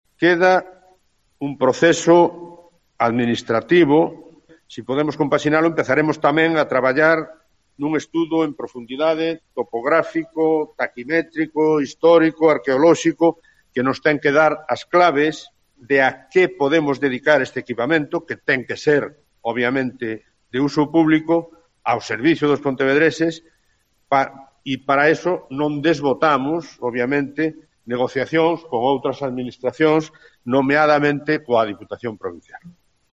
Declaraciones de Miguel Anxo Fernández Lores, alcalde de Pontevedra